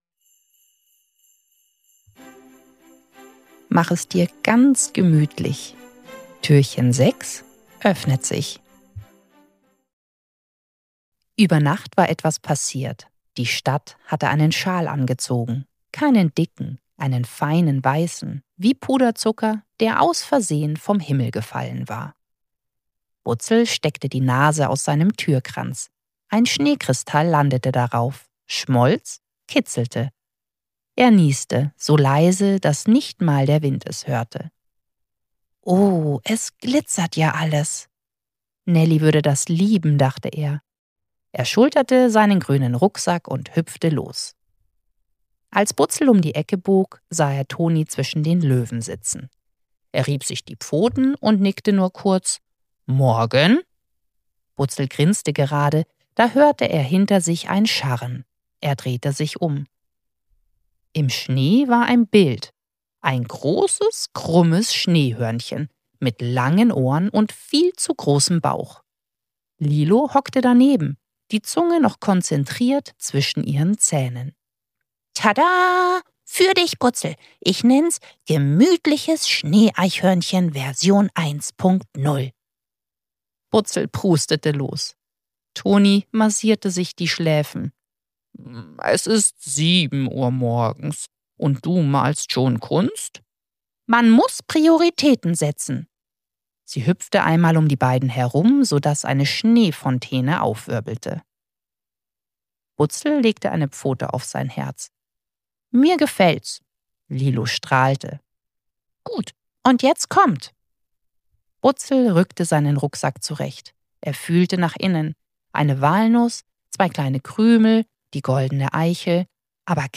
6. Türchen – Butzel und die Musik im Schnee ~ Butzels Adventskalender – 24 Hörgeschichten voller Herz & kleiner Wunder Podcast